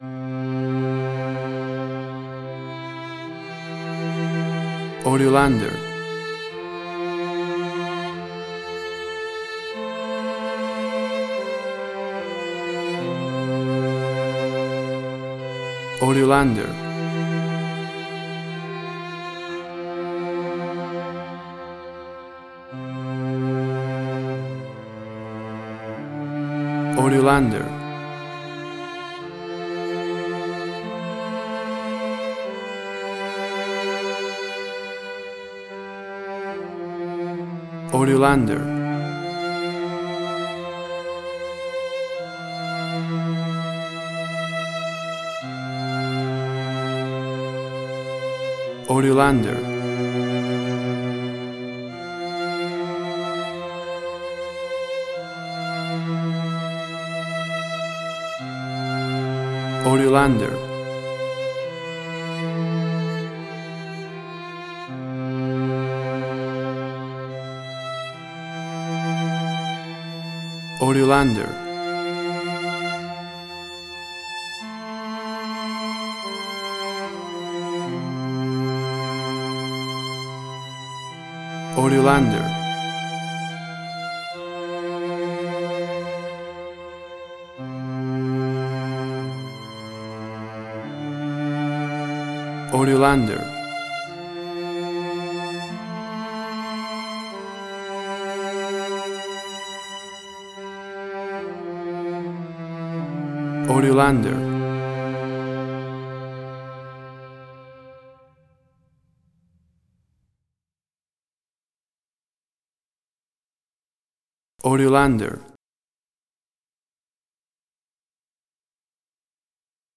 Suspense, Drama, Quirky, Emotional.
Tempo (BPM): 73